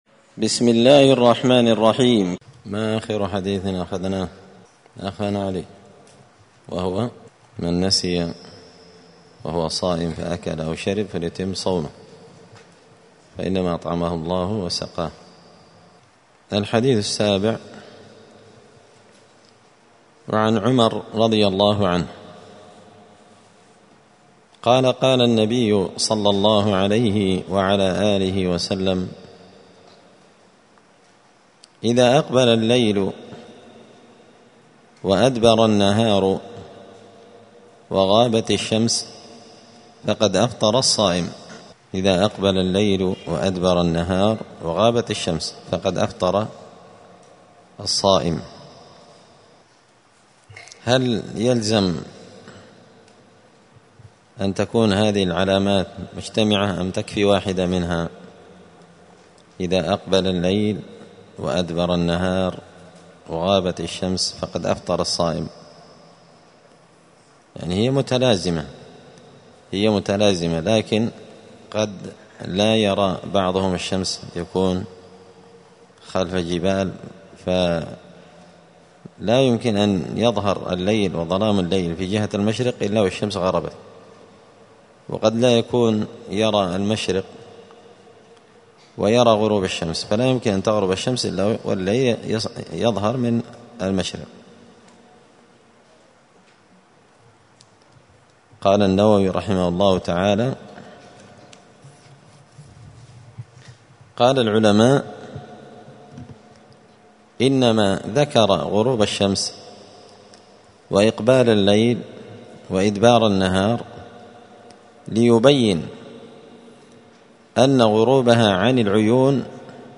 دار الحديث السلفية بمسجد الفرقان بقشن المهرة اليمن
*الدرس الثاني عشر (12) {حكم من أفطر قبل الوقت شاكا بدخول الوقت…}*